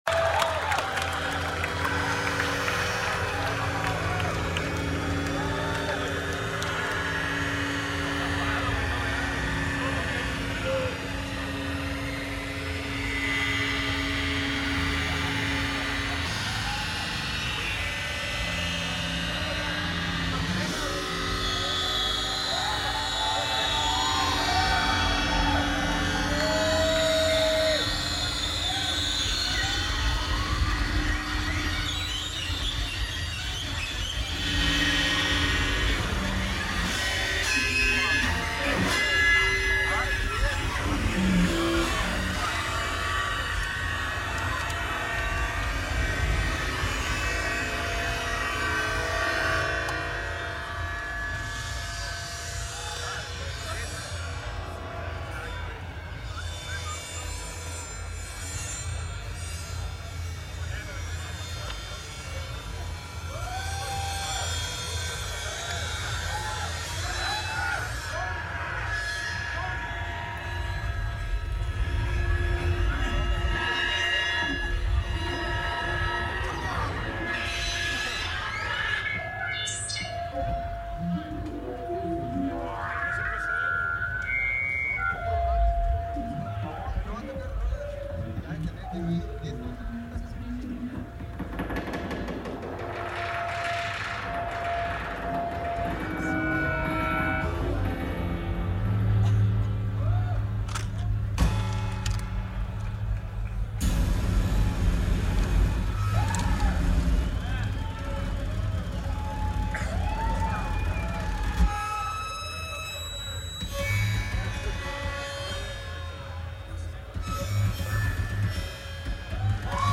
Empire Polo Fields
Coachella Valley Music and Arts Festival